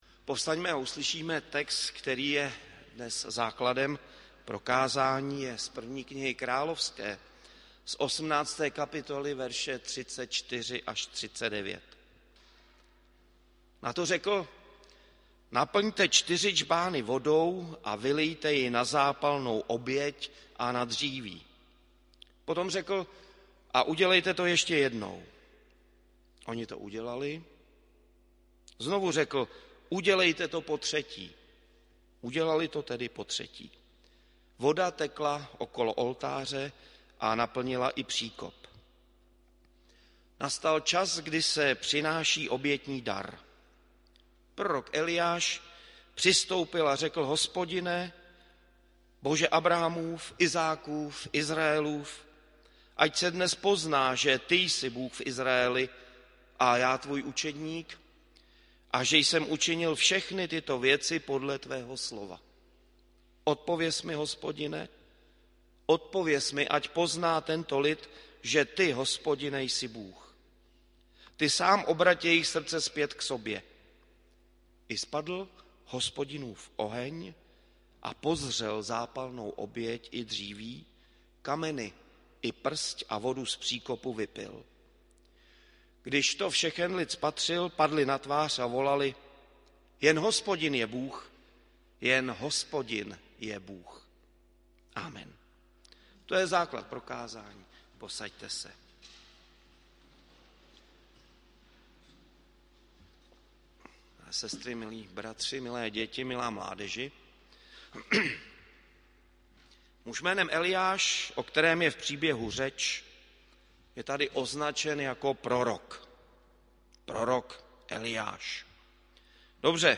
Dnes jsme slavili rodinnou neděli společně s kazatelskou stanicí Nýřany.
audio kázání